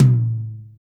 Tom
Original creative-commons licensed sounds for DJ's and music producers, recorded with high quality studio microphones.
low-mid-tom-single-hit-a-key-12-fyl.wav